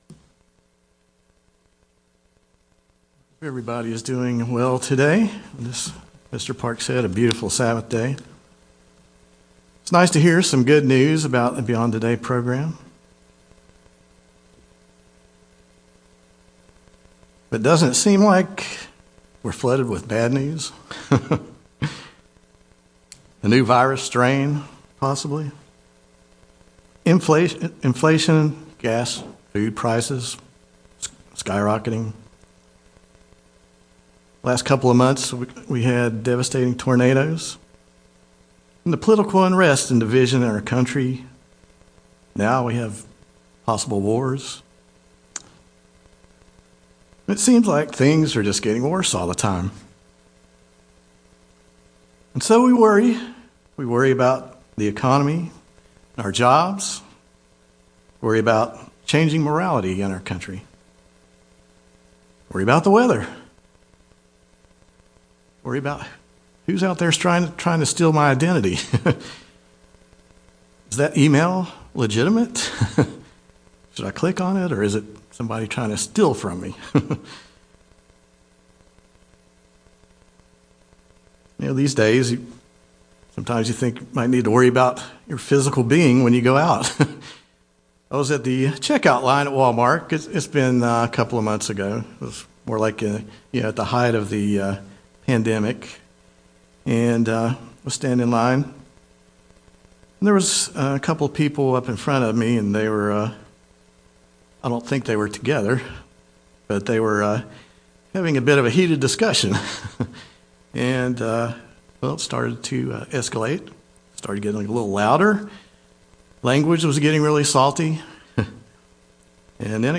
Sermons
Given in Greensboro, NC